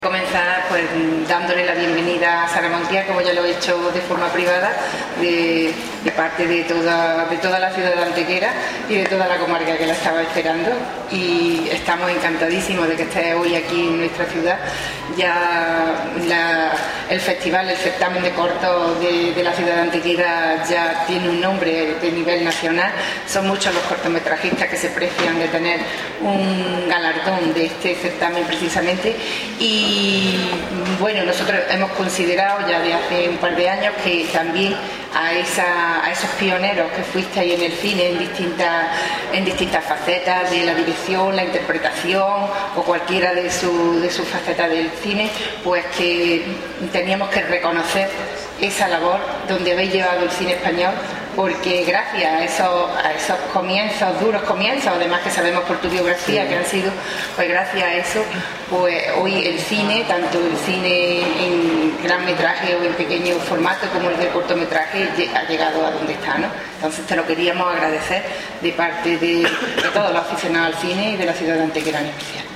Cortes de voz
Audio: concejala de Cultura   1133.88 kb  Formato:  mp3